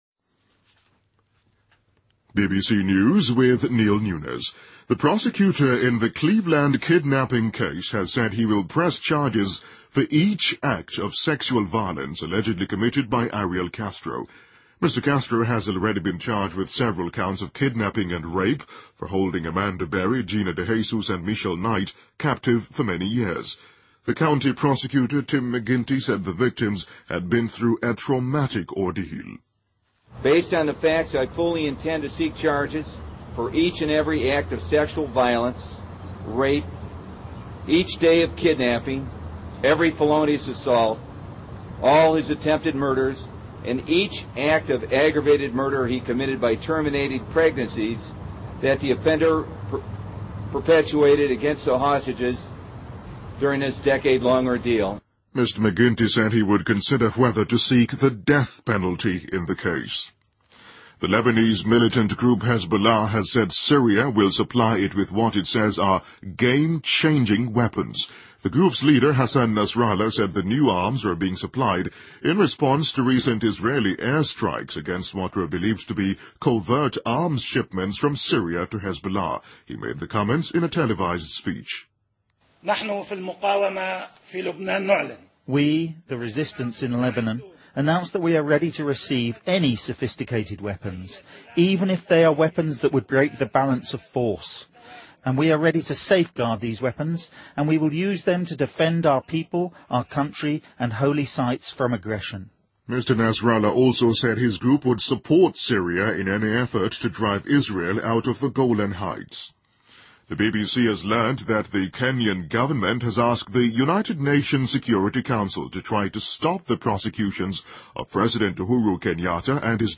BBC news,2013-05-10